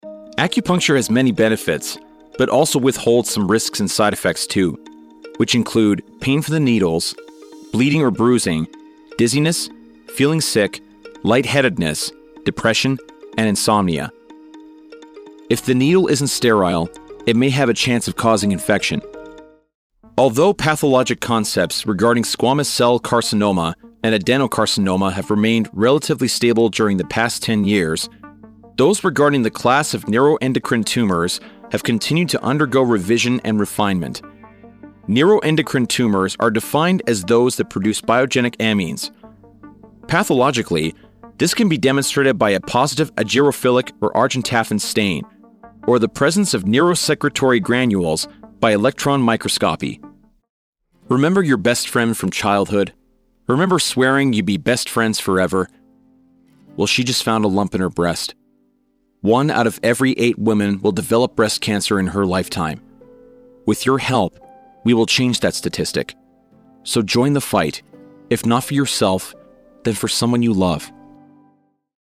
Male
Yng Adult (18-29), Adult (30-50)
Bass-baritone, melodious, articulate and masculine.
Medical Narrations
0916Medical_Explainer_Demo_.mp3